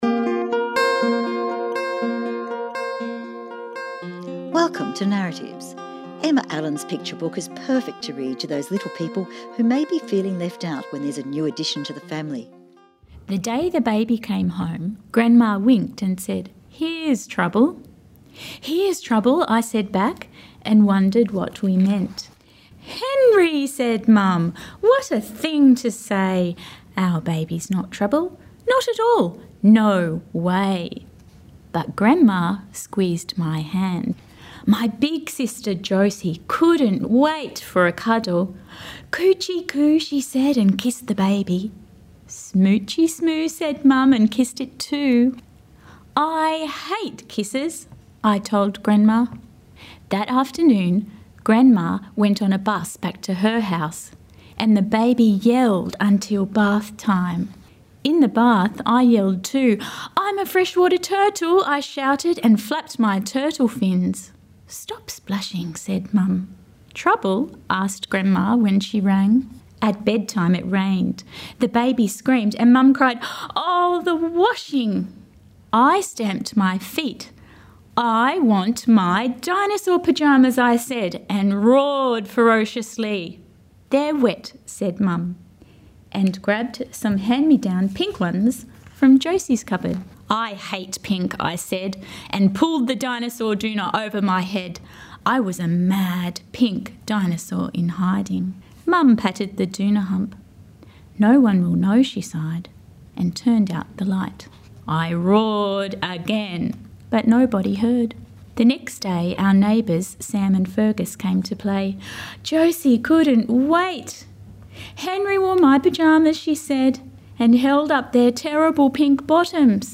Recorded at Sydney Writers Festival 2016